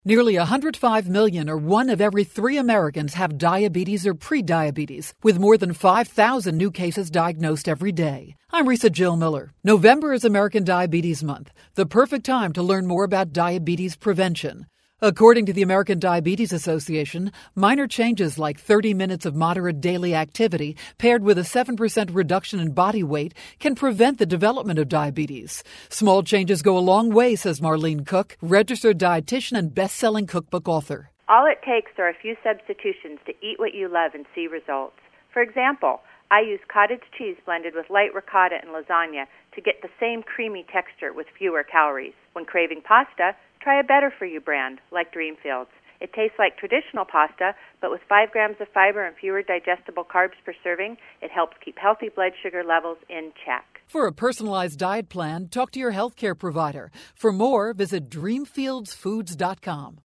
November 12, 2012Posted in: Audio News Release